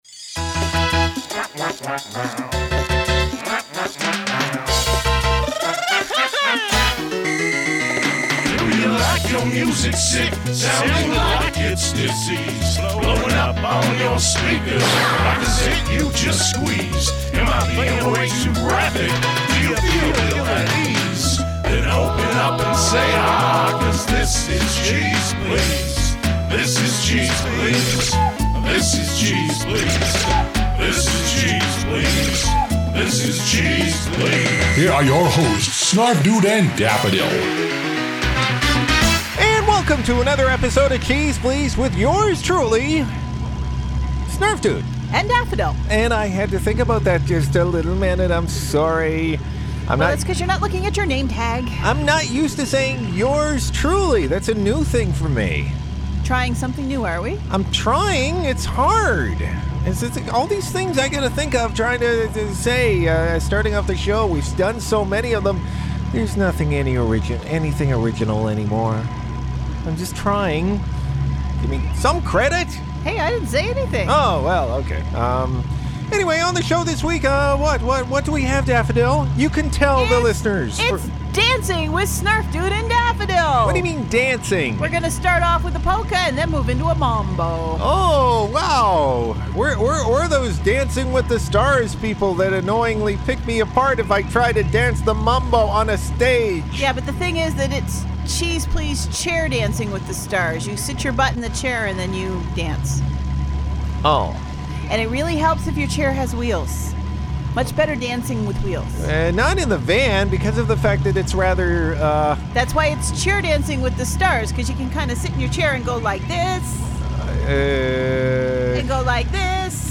It's MAMBO THIS WEEK!!!!